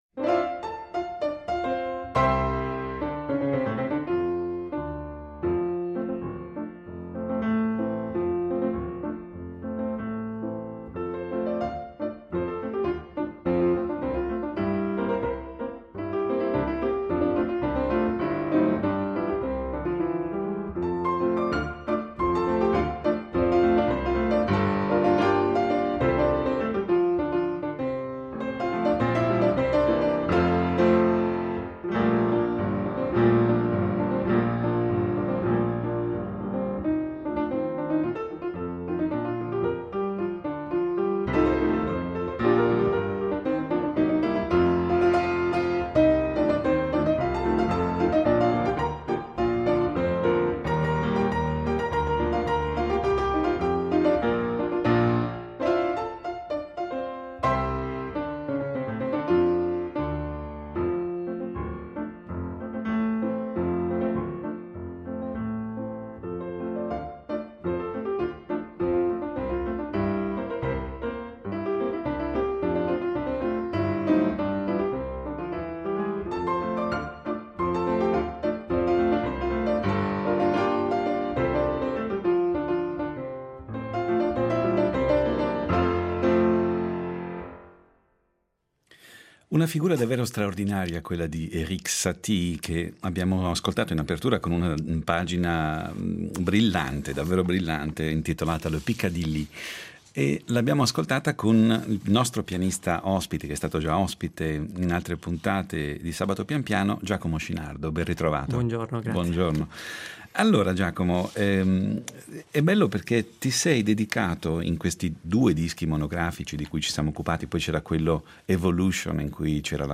il pianista italiano
pianoforte